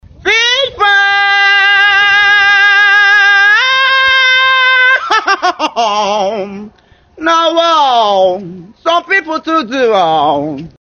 Fish pie | sound effect for comedy
Sound effect for comedy 🤣 Fish pie (comedy sound effect)
Fish-pie-comedy-sound-effect.mp3